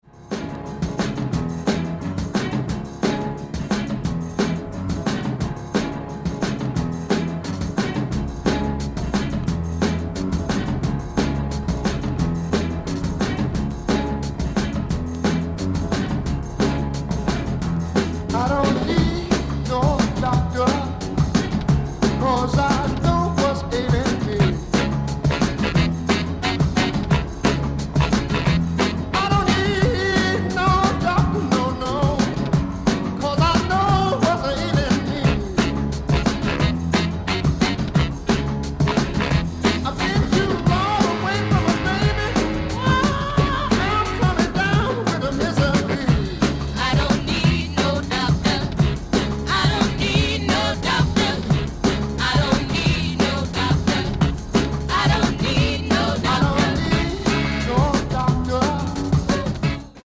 Latin-soul gems. Massive dance floor appeal!